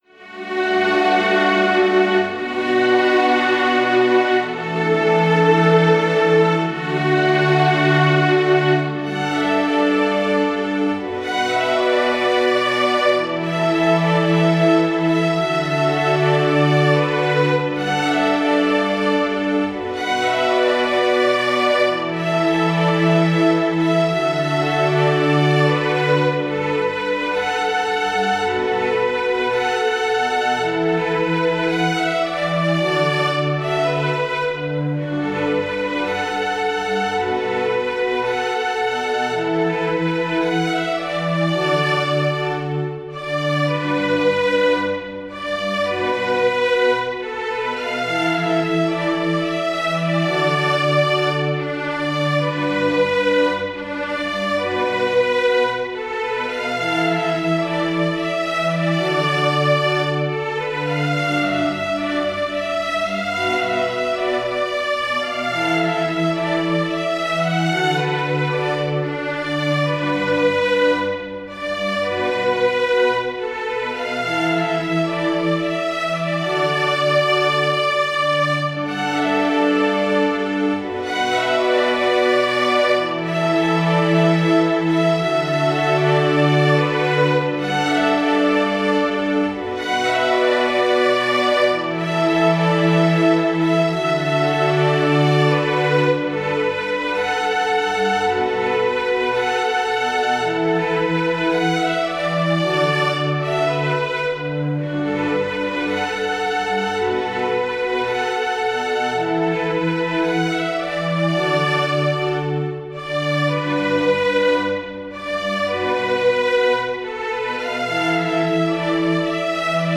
para Quarteto de Cordas
● Violino I
● Violino II
● Viola
● Violoncelo